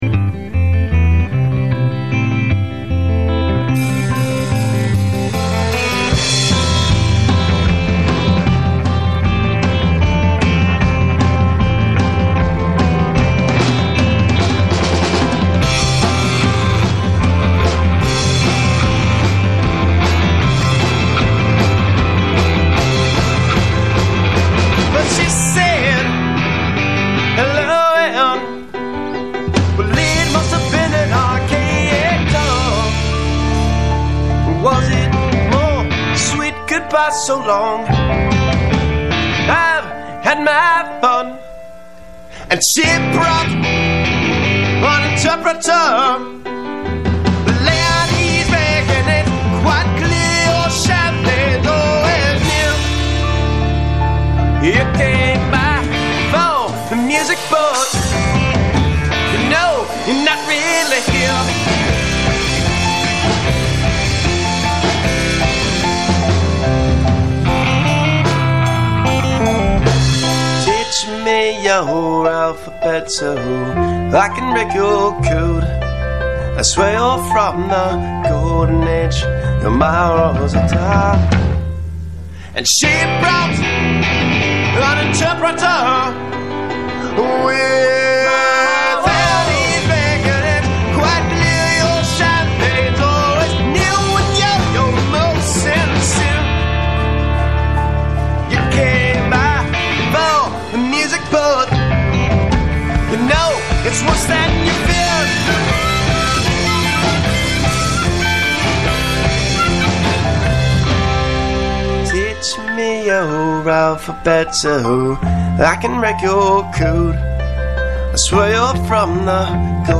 radio show with musical guests Hardworker.